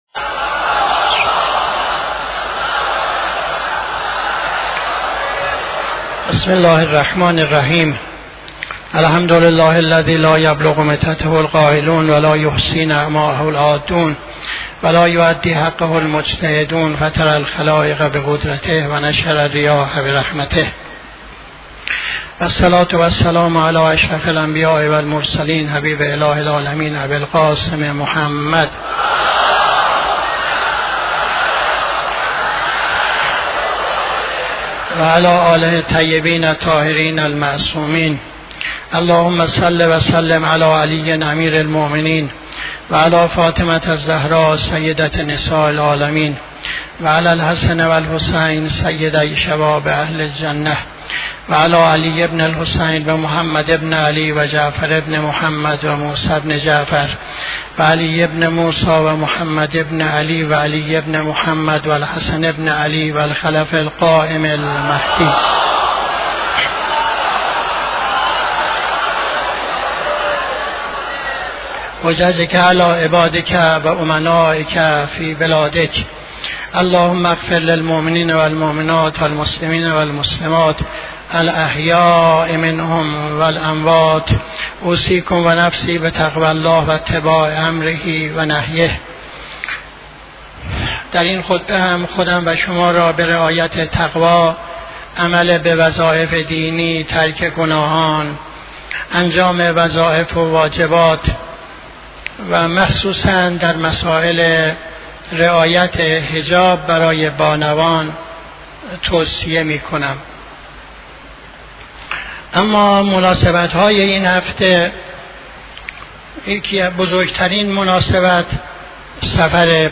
خطبه دوم نماز جمعه 07-05-79